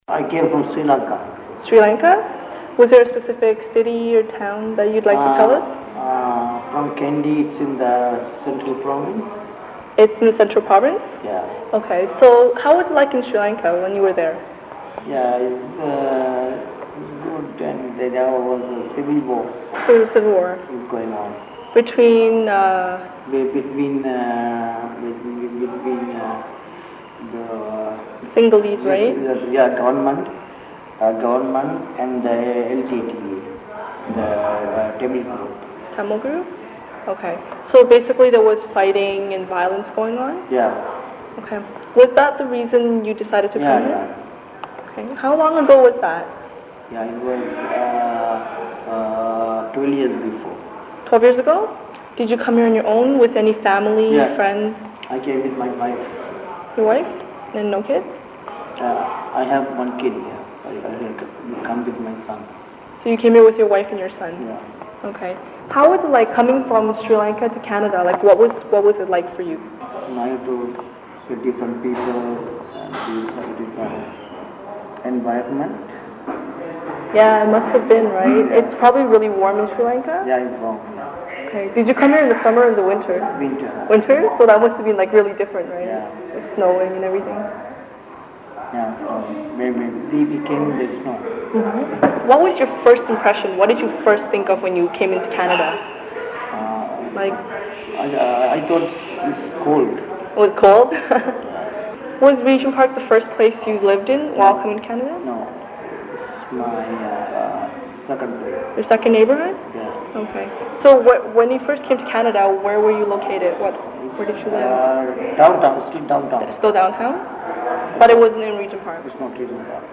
Journey Home is a story-telling project that explores the journey from homeland to Canada, through the voices of Regent Park residents.